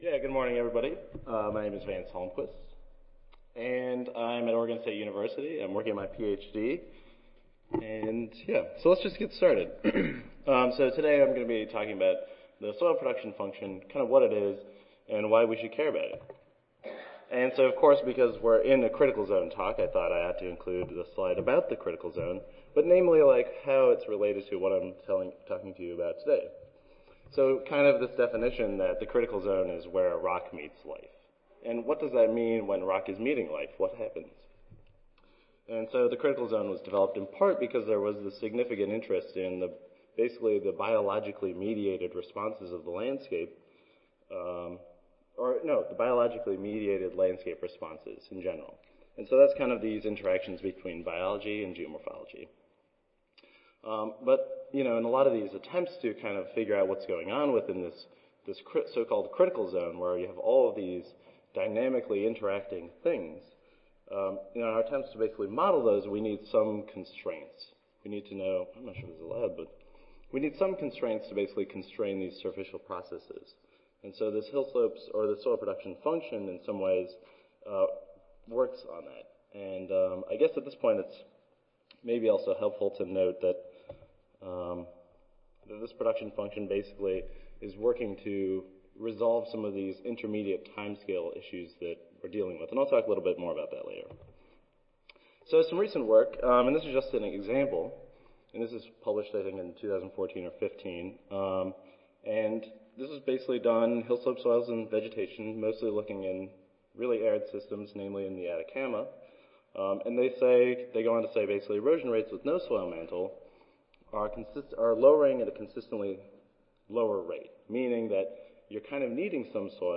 See more from this Division: SSSA Division: Pedology See more from this Session: Symposium--Pedology & Earth's Critical Zone